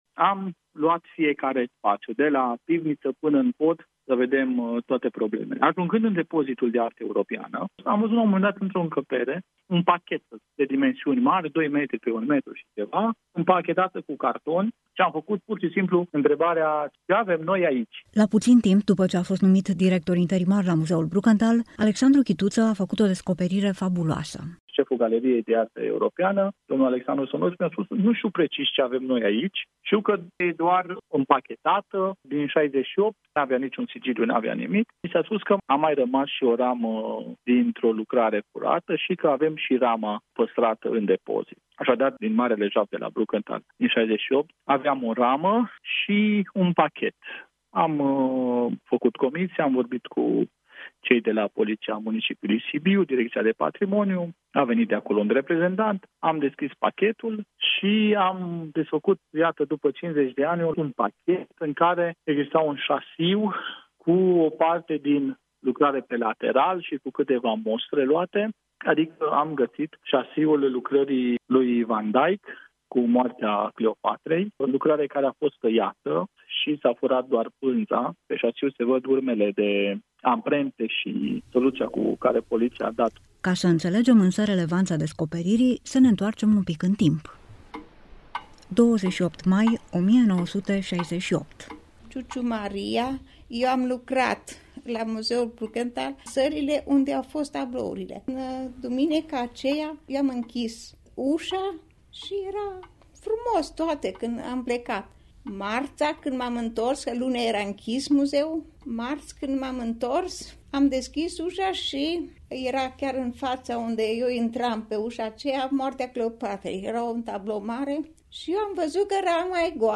REPORTAJ-BUN-BRUKENTHAL-pentru-Lumea-EFM.mp3